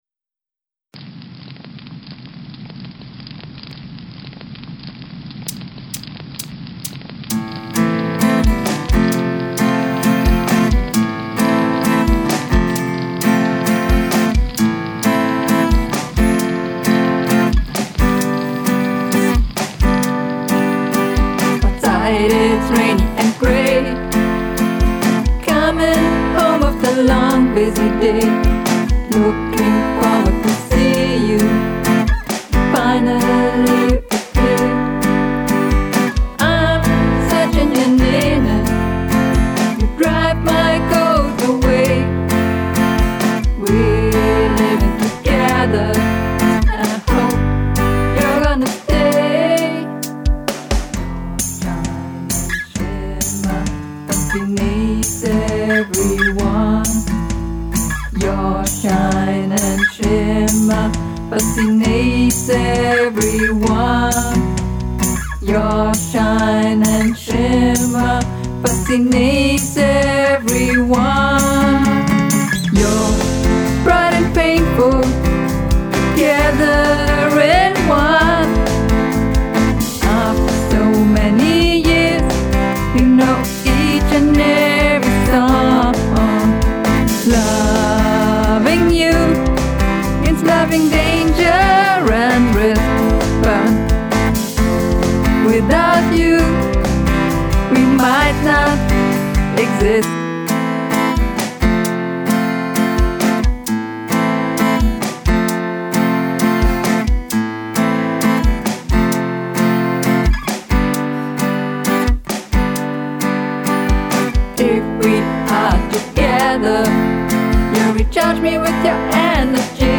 Hallo, das ist mein 1. Song den ich mit Cubase aufgenommen habe.
Leider ist er irgendwie 'dumpf' geworden.
Auch ist die Raumverteilung nicht so gut und die Steigerung im Pre-Chorus ist nicht so gelungen.
Ach ja, das Rauschen zu Beginn und Ende soll Feuer sein, das erkennen leider auch nicht alle.